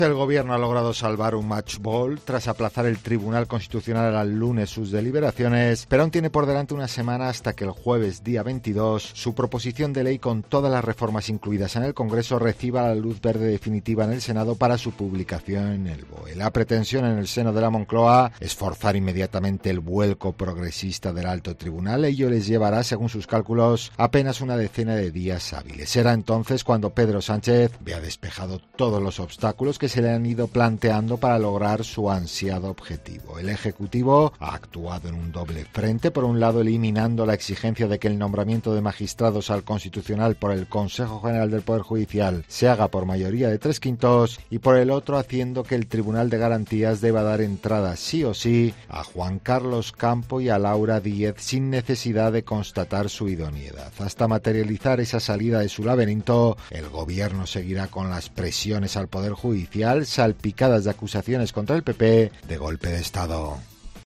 Te cuenta todos los detalles